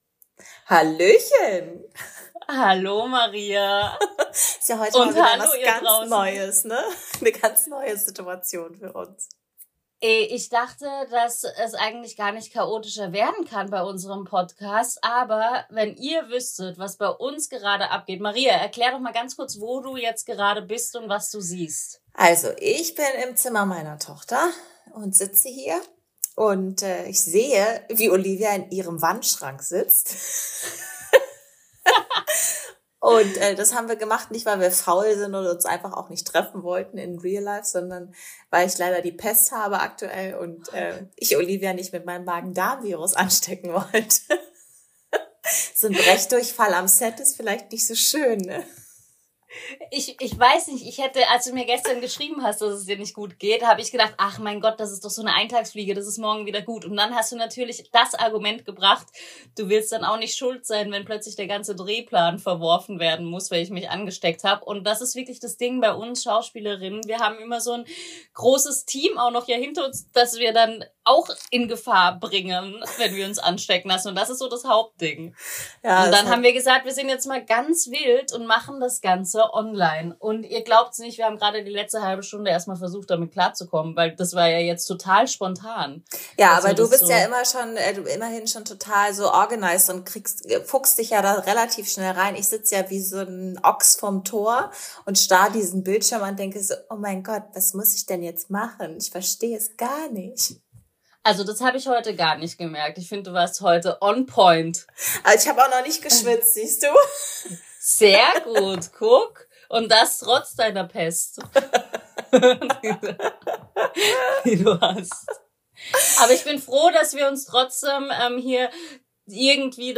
Das und viele andere Fragezeichen, versuchen wir, in dieser etwas anderen und akustisch stürmischen Folge zu beantworten.